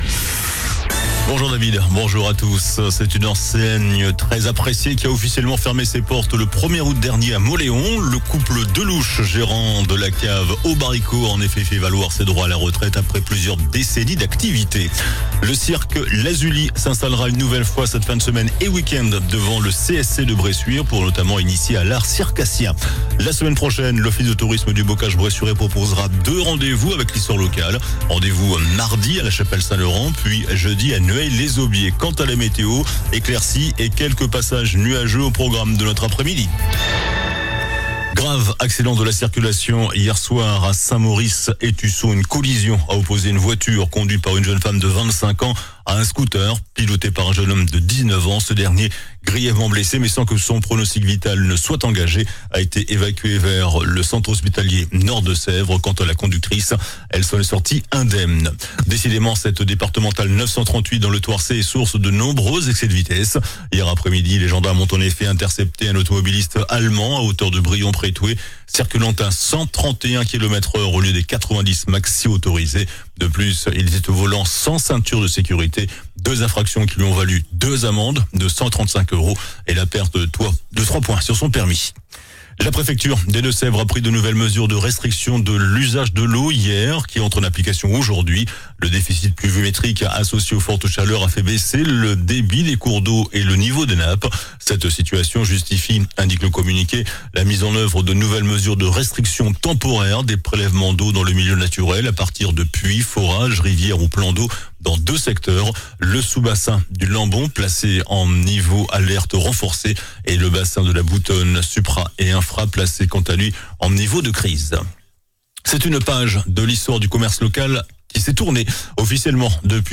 JOURNAL DU MERCREDI 20 AOÛT ( MIDI )